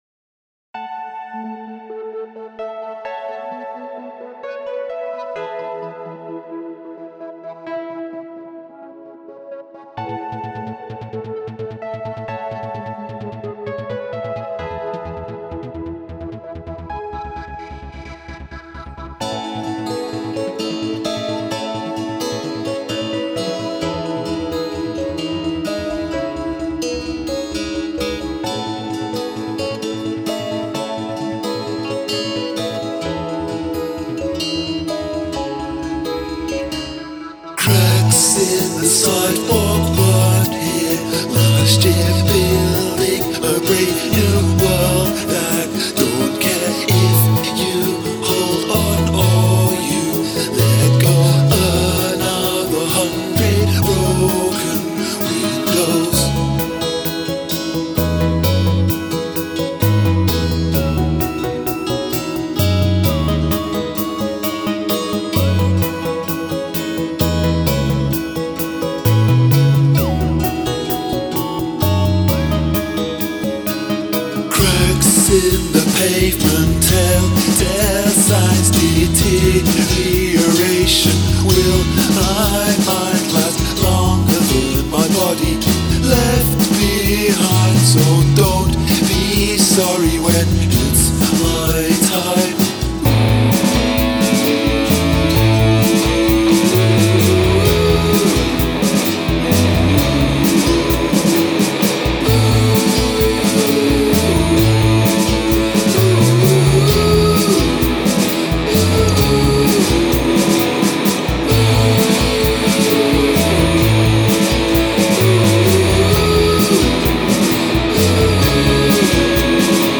(verses in 5/8, outro in 15/8)